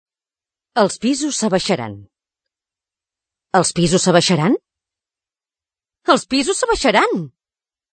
text oral
Cadascuna d’aquestes unitats d’entonació que ens han servit d’exemple és una modalitat d’oració i té una funció diferent. La primera és una modalitat declarativa, la segona, interrogativa i, la tercera, exclamativa.